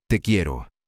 1. "Te" rhymes with "day": It's a short, crisp sound.
2. "Quiero" = "KYEH-roh": The "qu" makes a "k" sound, and the "ie" blends together.